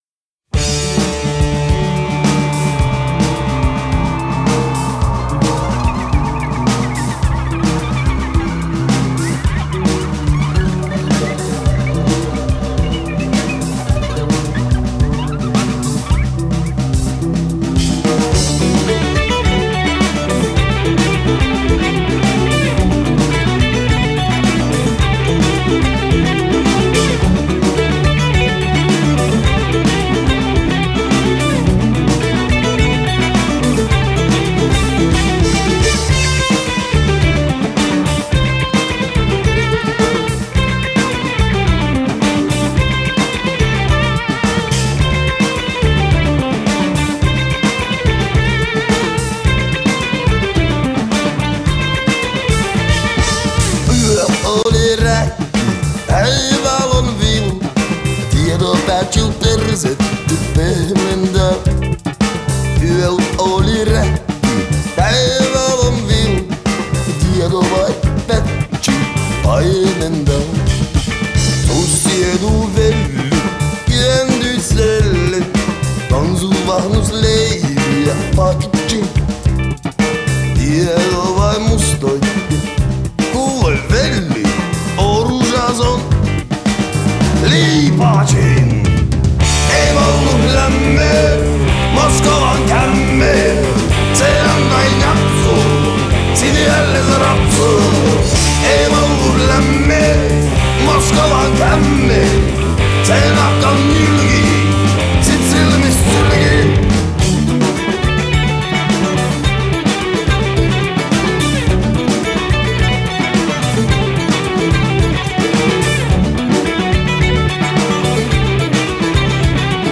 Bass Guitar
Drums
Guitar
Lap Steel Guitar, Mandolin, Claves, Bass, Vocals
Shaker